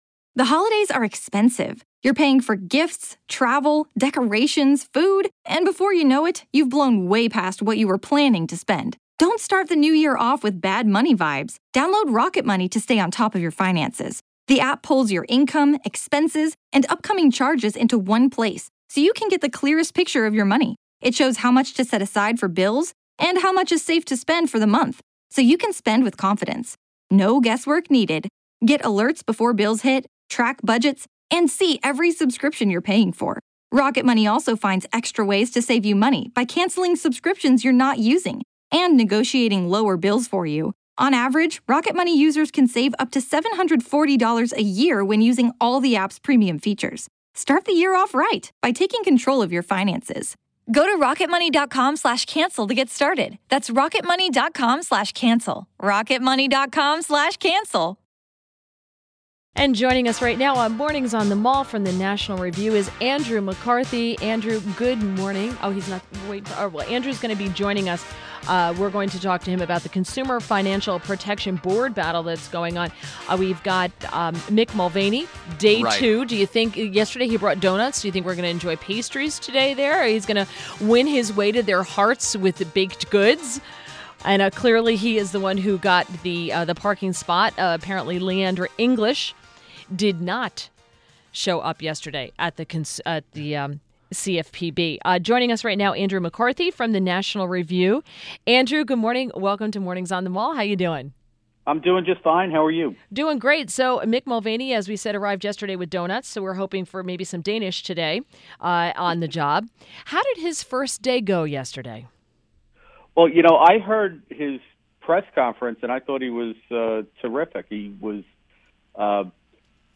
WMAL Interview - ANDREW MCCARTHY - 11.28.17